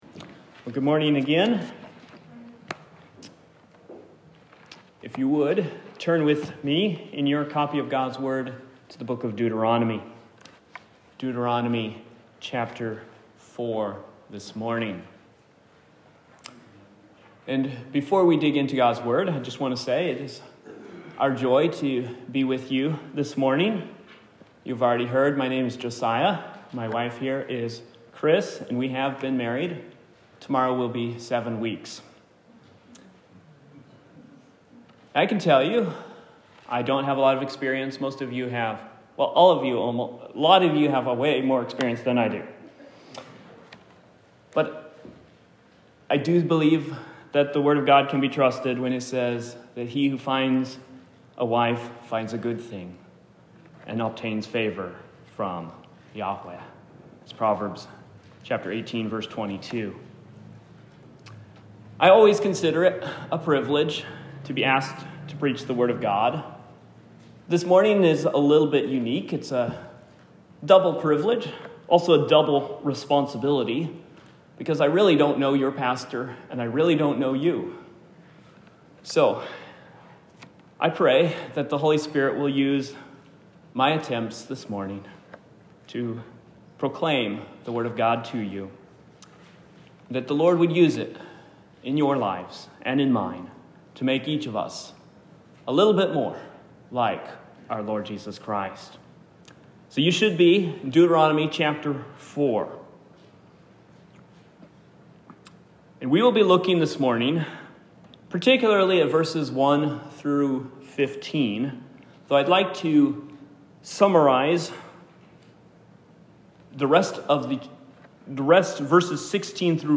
Topic: Guest Speaker, Sunday Morning